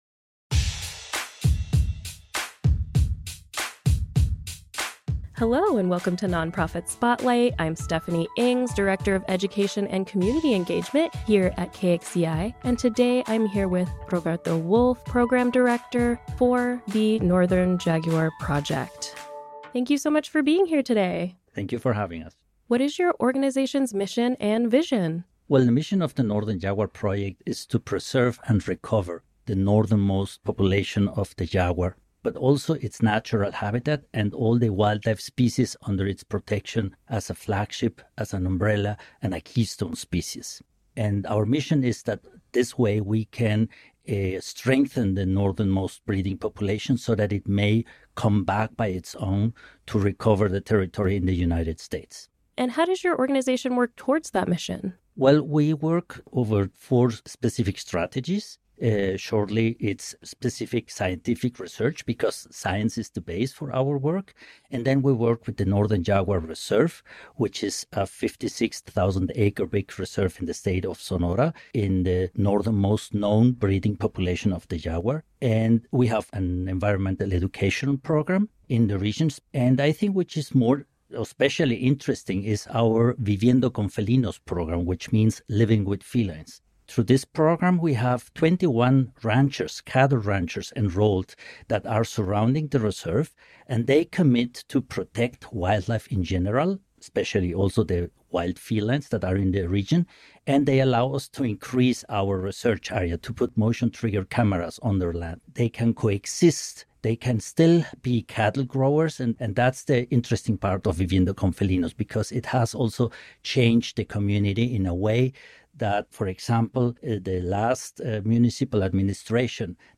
KXCI Community Radio